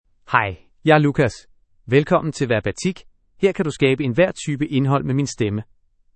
Lucas — Male Danish AI voice
Voice sample
Listen to Lucas's male Danish voice.
Male
Lucas delivers clear pronunciation with authentic Denmark Danish intonation, making your content sound professionally produced.